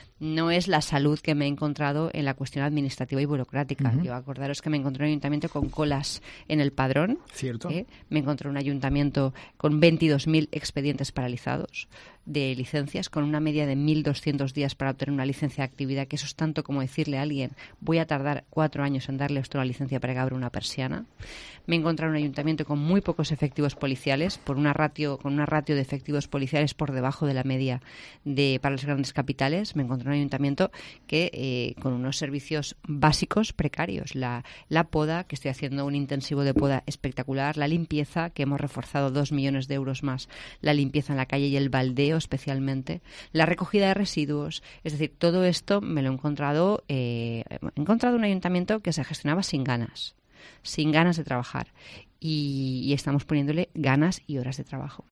La alcaldesa de Valencia, María José Catalá, ha pasado por los micrófonos de COPE para hacer un balance de sus primeros tres meses como primera edil de la ciudad.